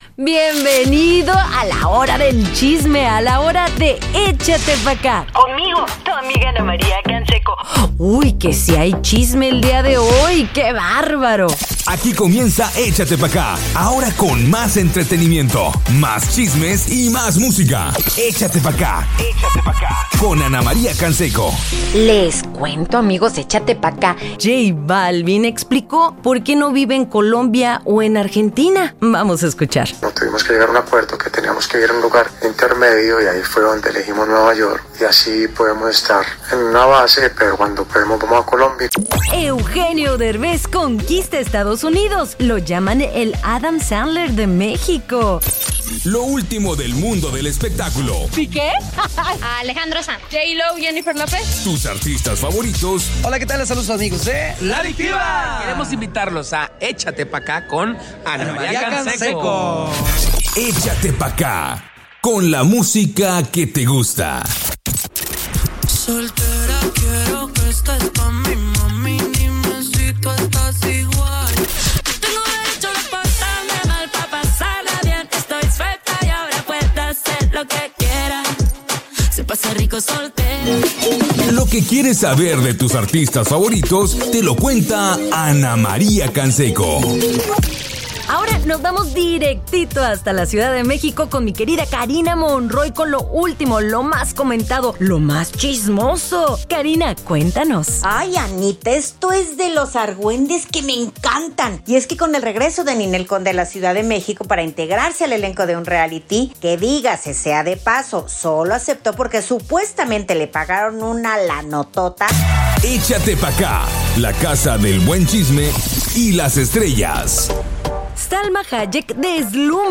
Nuevo Pop
Music, Entertainment & Lots of Laughs!
Ana Maria Canseco brings her signature warmth, humor, and cultural insight to this lively daily show. Échate Pa’Cá is formatted to fit Mexican Regional stations and covers music, lifestyle, trending news, and interviews — all with Ana Maria’s unique voice and charisma.